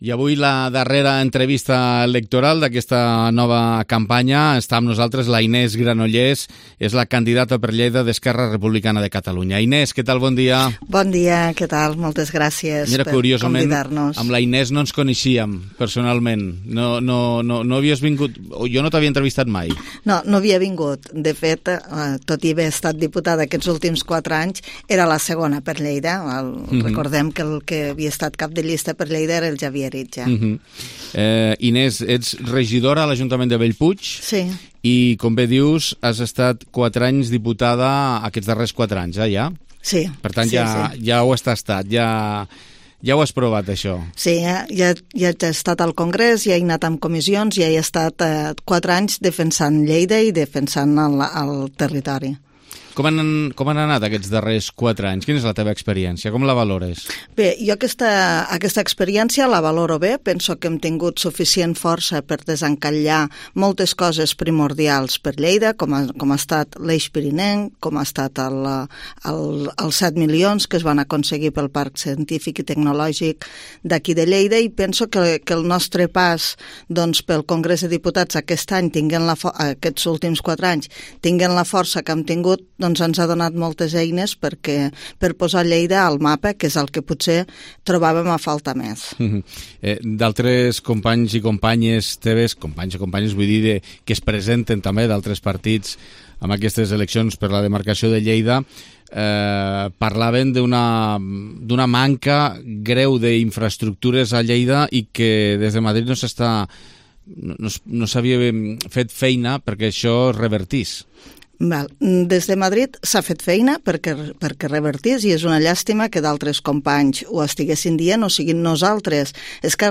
Entrevista Campanya Electoral 23J2023 - Inés Granollers - ERC